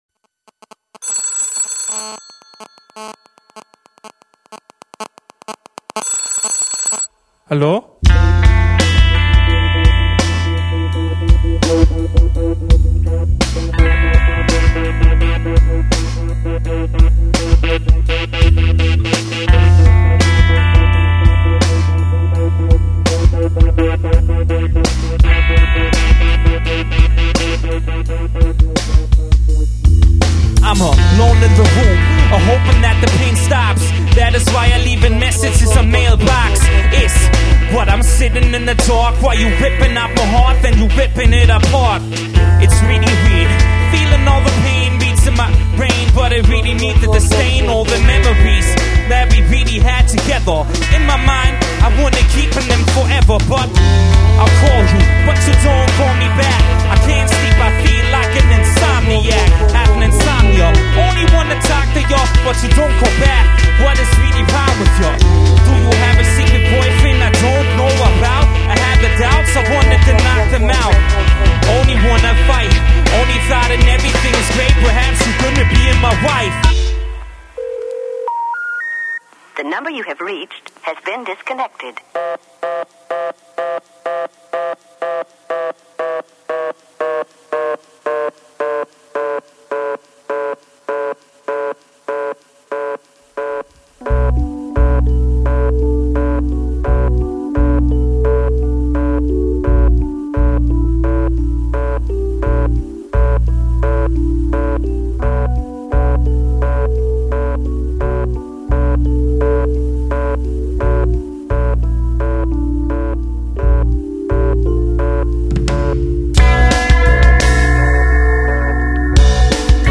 Münchner Künstlerkollektiv